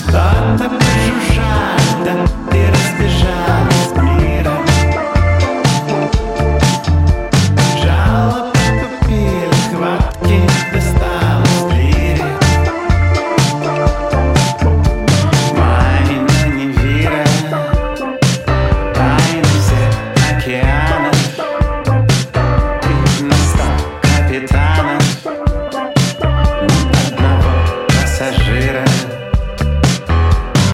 • Качество: 128, Stereo
рок